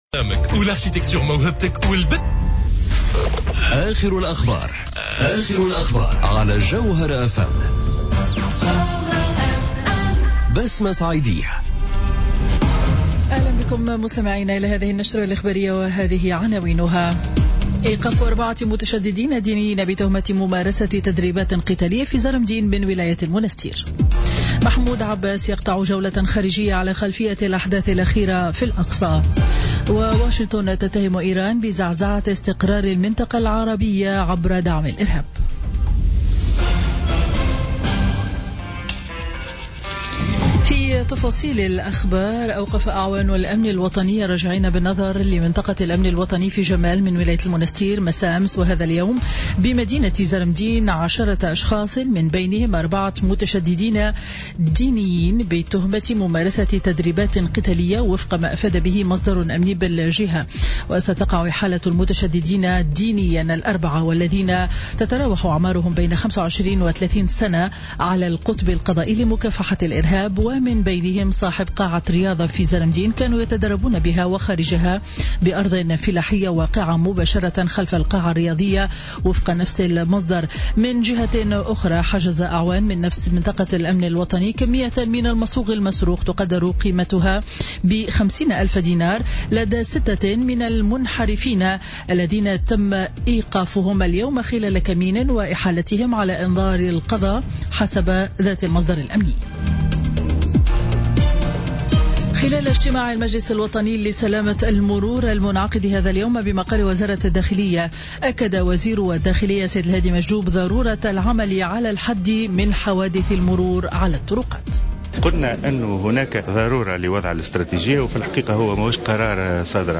نشرة أخبار السابعة مساء ليوم الأربعاء 19 جويلية 2017